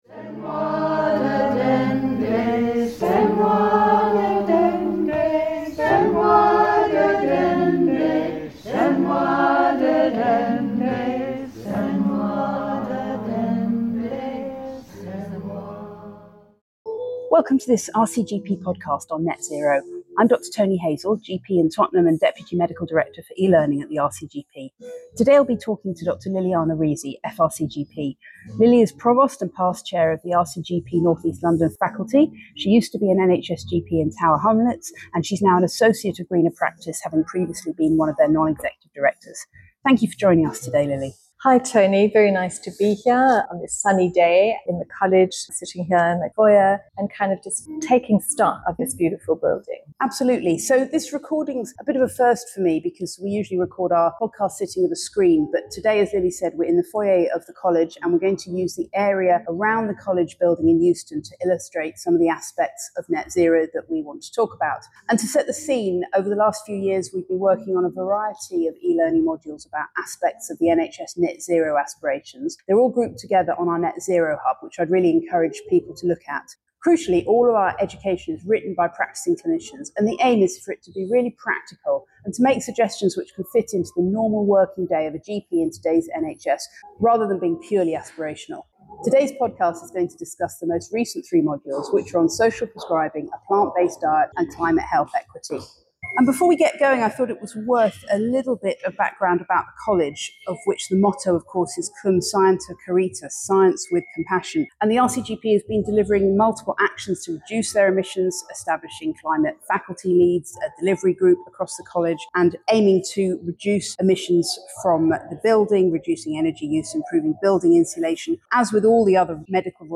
This podcast is produced by the RCGP eLearning and Essential Knowledge Update teams and features discussions with clinical experts about a range of key topics in primary care.